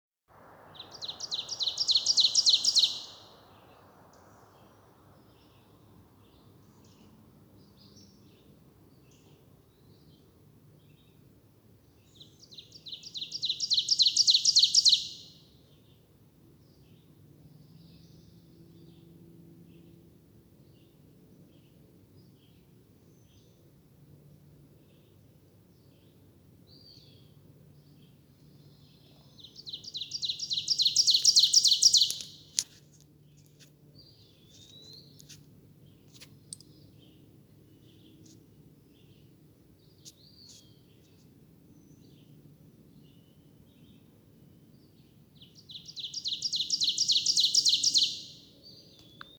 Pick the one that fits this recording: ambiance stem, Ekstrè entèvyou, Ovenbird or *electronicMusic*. Ovenbird